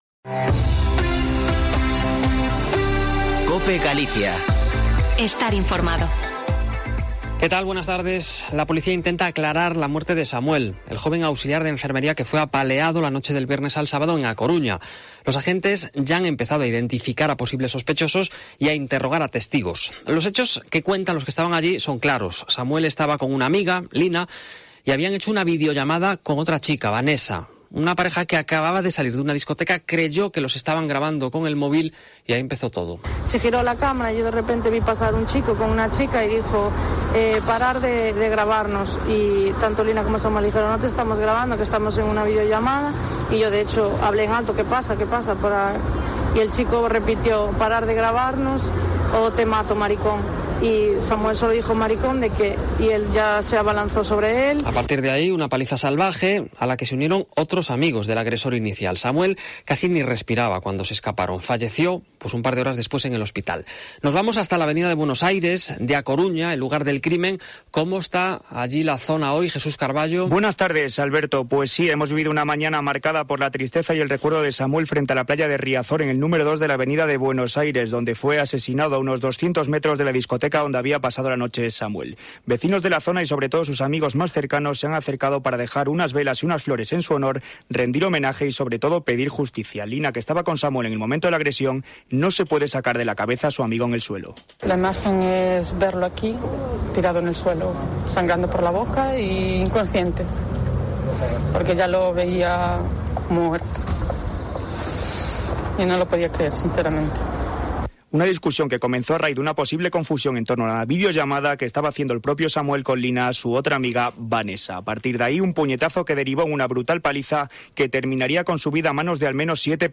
Informativo Mediodia en Cope Galicia 05/07/2021. De 14.48 a 14.58h